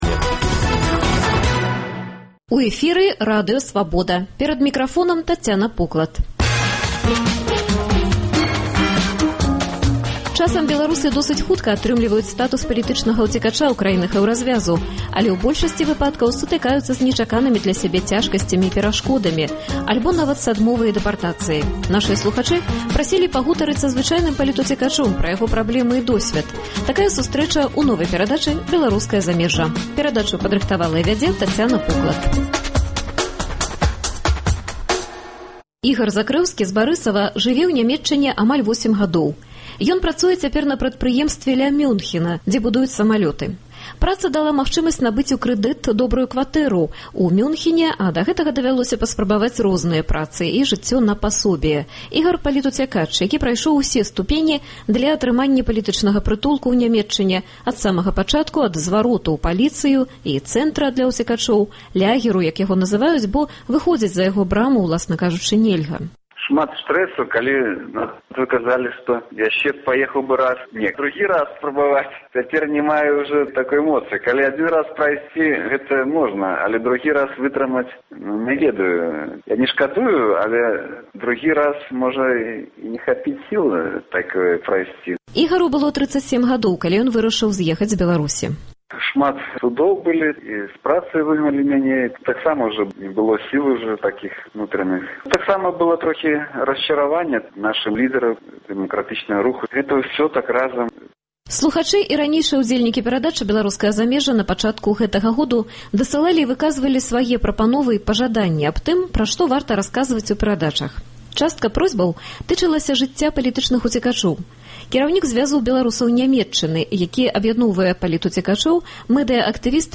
Часам беларусы досыць хутка атрымліваюць статус палітычнага ўцекача ў краінах Эўразьвязу – але ў большасьці выпадкаў сутыкаюцца зь нечаканымі для сябе цяжкасьцямі і бюракратычнымі перашкодамі альбо нават з адмовай і дэпартацыяй. Гутарка з палітуцекачом пра яго праблемы і досьвед у новай перадачы Беларускае замежжа.